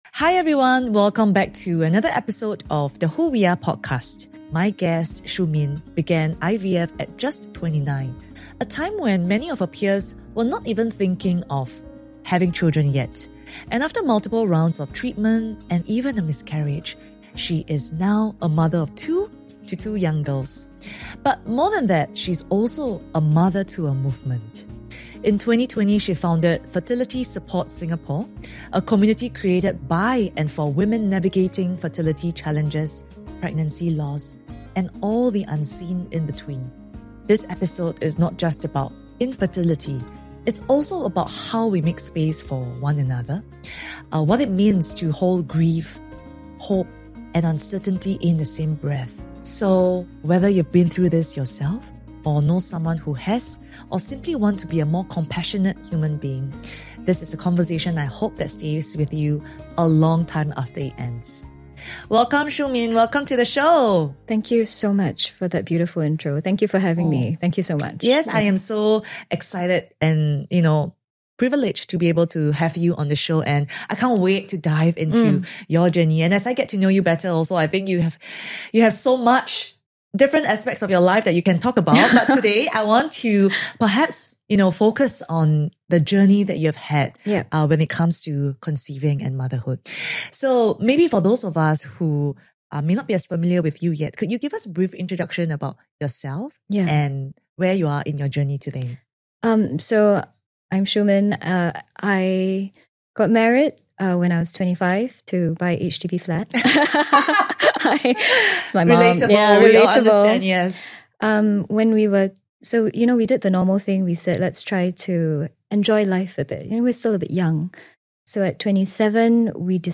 It’s a kind of grief that lives quietly in the background of so many lives…and often, no one talks about it. In this heartfelt conversation